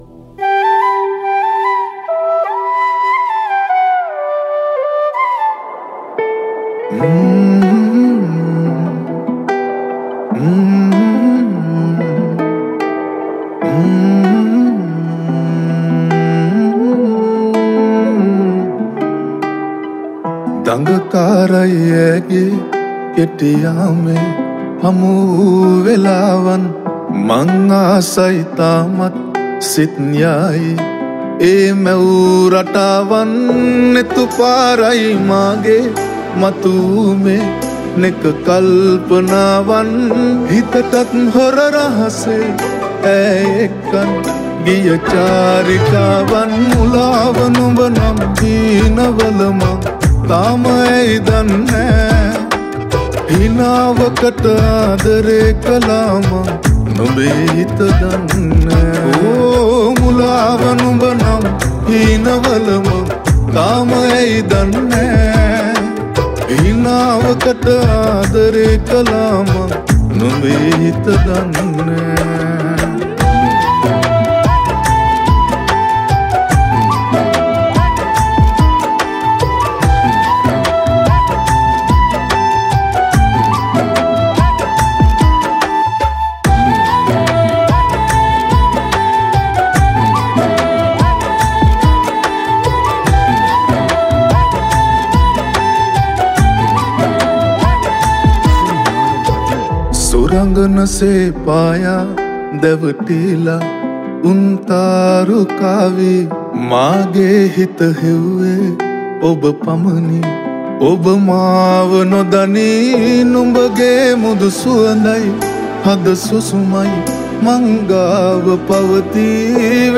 Flute
Backing Vocal